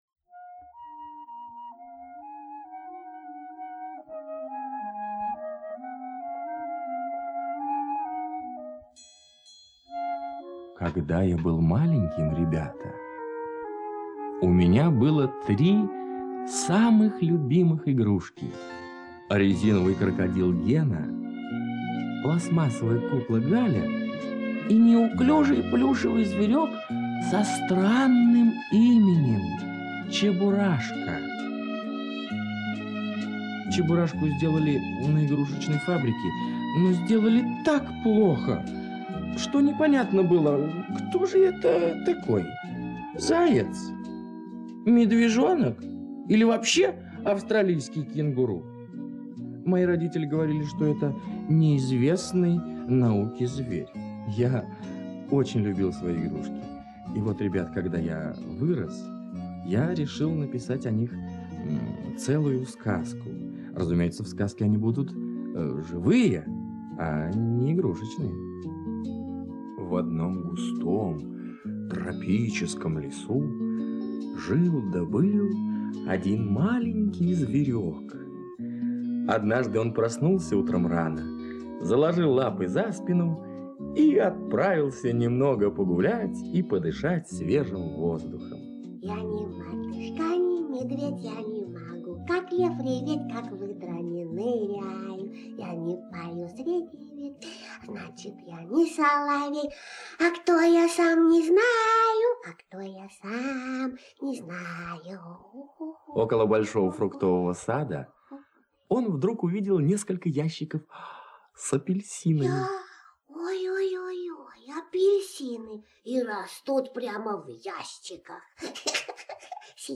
Чебурашка - радиоспектакль.
-- / -- volume_up volume_mute audiotrack Чебурашка - Успенский Э.Н. Инсценировка по мотивам произведений советского писателя Эдуарда Успенского рассказывающих о забавных приключениях сказочного героя Чебурашки. Запись с грампластинки Всесоюзной студии звукозаписи "Мелодия".
cheburashka-audiospektakl.mp3